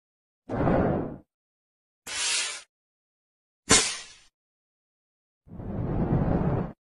how Motorcycle engine works sound effects free download